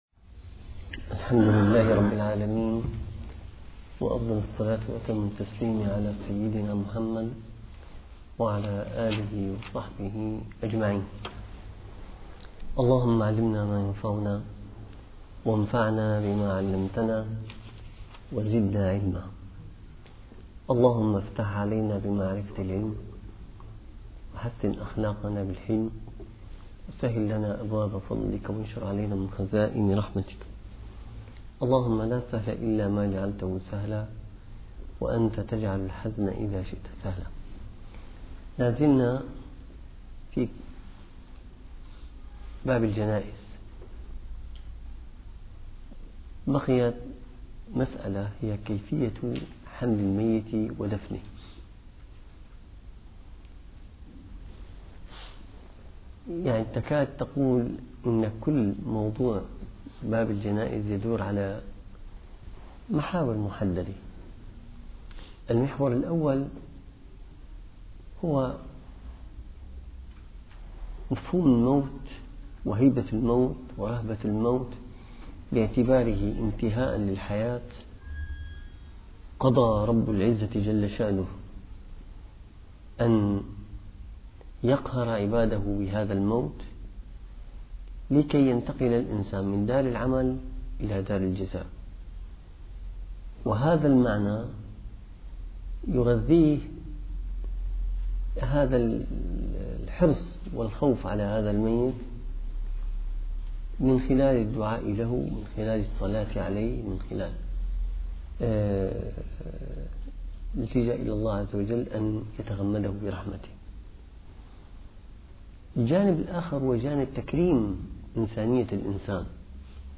- الدروس العلمية - الفقه الشافعي - نهاية التدريب - الدرس التاسع عشر: الجنازة - الزكاة